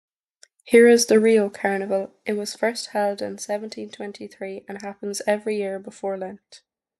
A narrator briefly introduces several famous festivals from history, sharing when and where they took place.